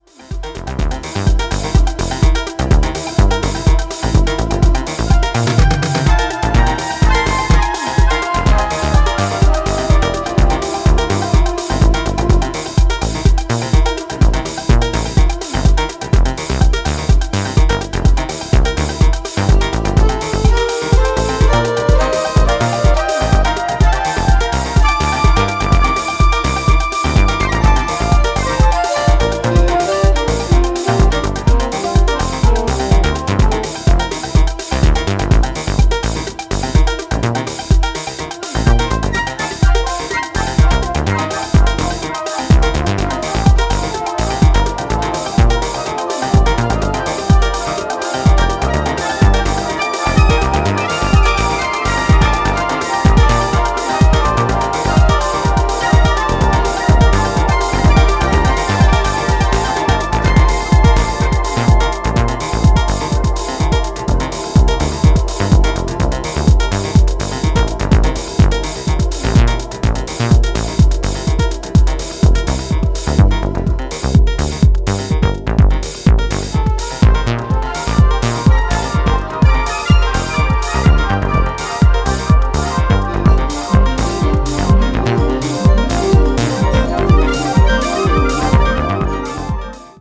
limited runs of live takes recorded during